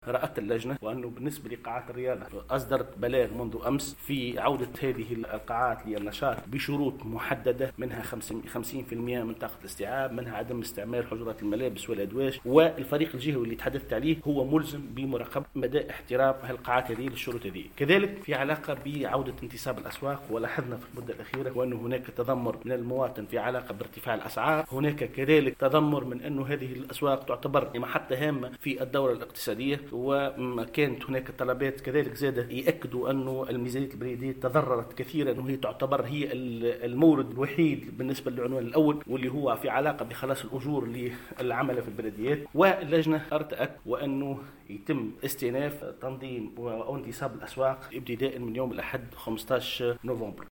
والي سيدي بوزيد